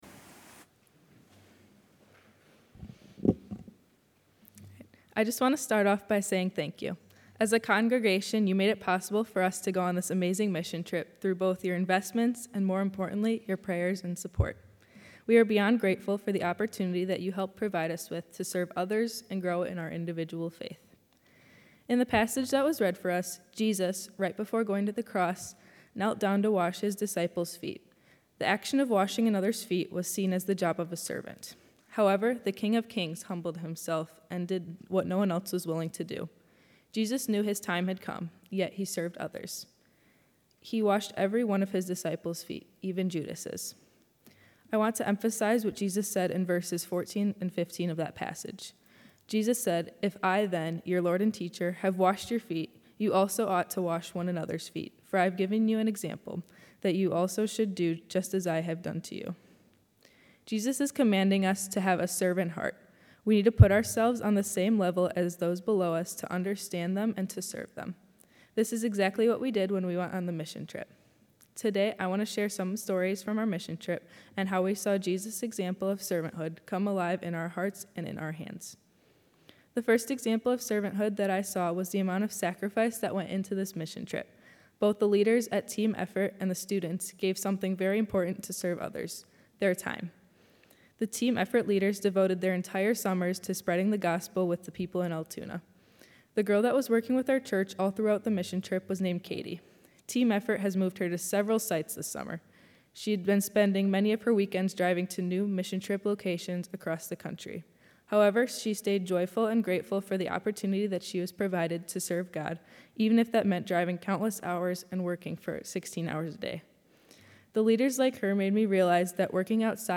Sunday, August 10 was Mission Trip Sunday where our students who participated on the 2025 mission trip led our worship services.